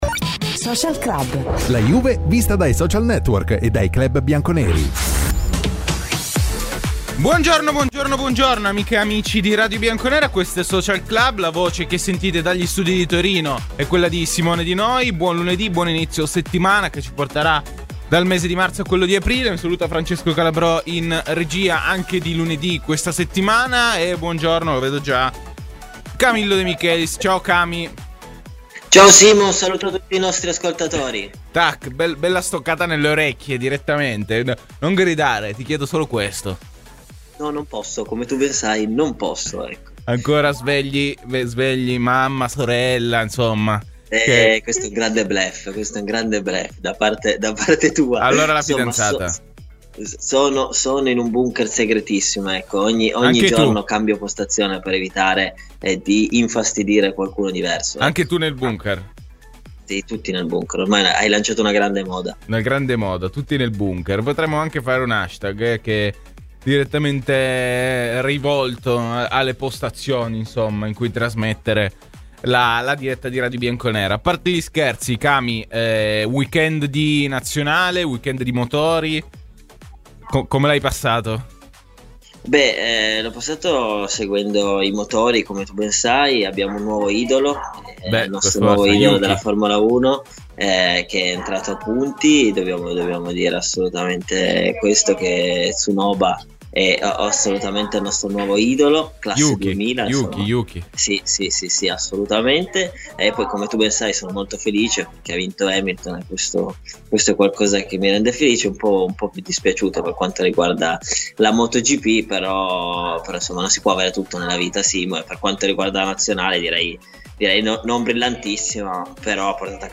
Clicca sul podcast in calce per ascoltare la trasmissione integrale.
(via Skype)